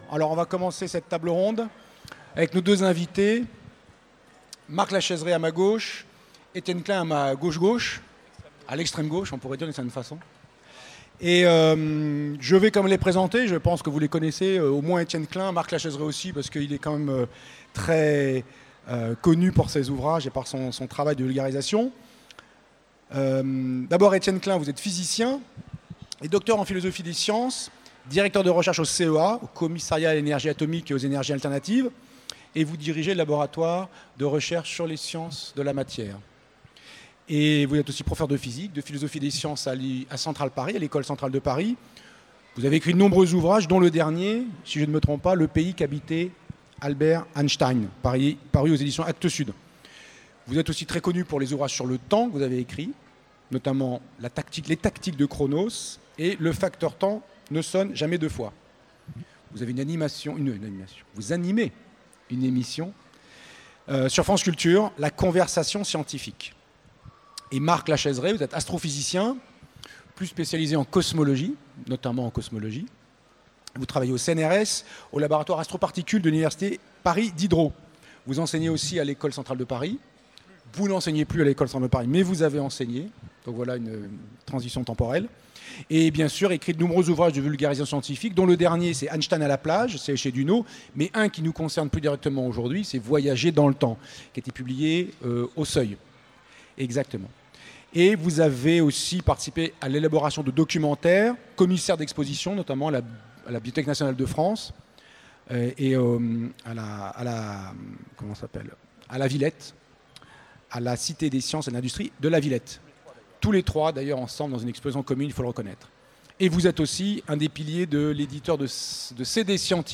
Utopiales 2017 : Conférence Le temps est-il une illusion ?